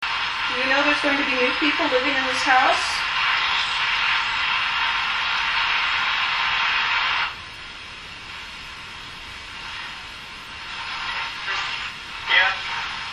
The location of this home is a private residence in Belfair.
Ghost Box Session: This is something that we are still experimenting with.